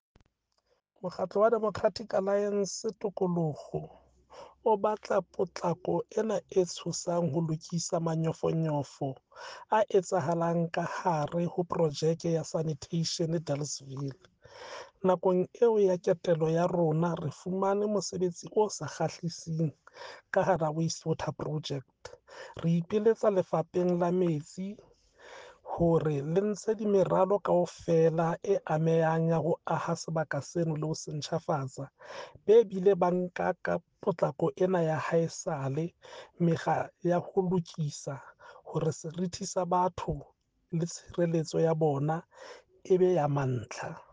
Sesotho soundbites by Cllr Hismajesty Maqhubu and Afrikaans soundbite by David Mc Kay MPL.